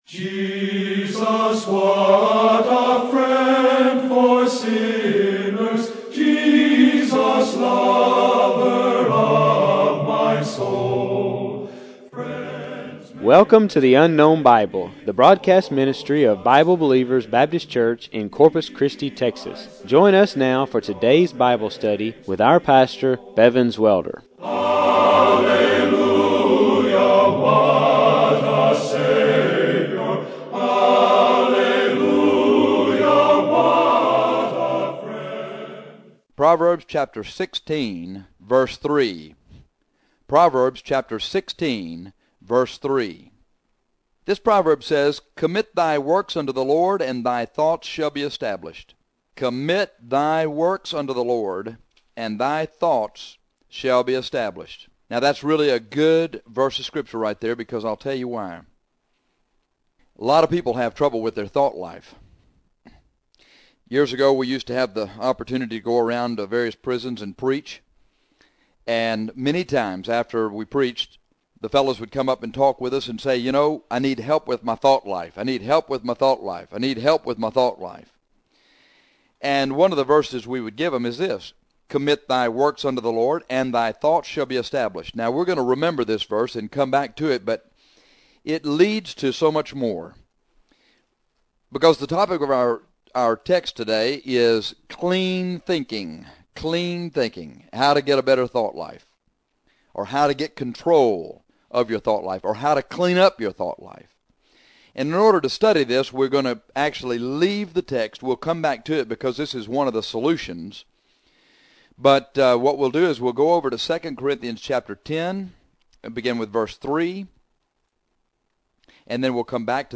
This is a lesson on how clean thinking develops clean thoughts.